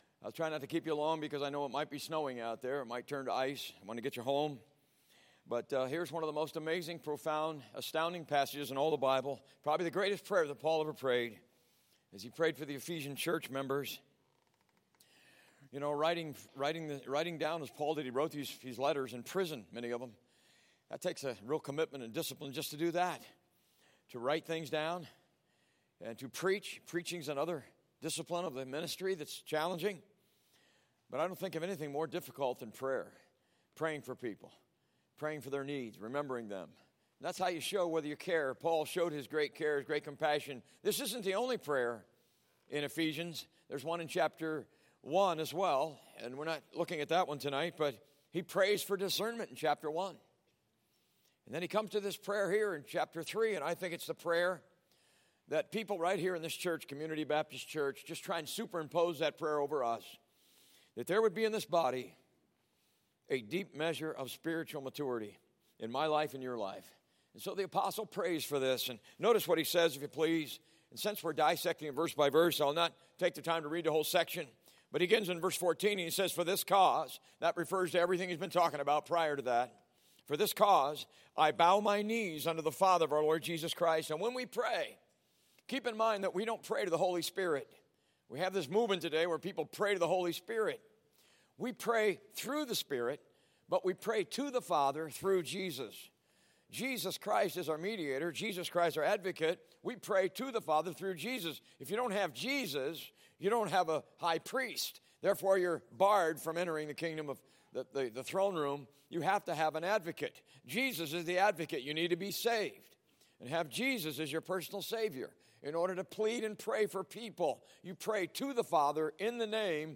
Service Type: P.M. Service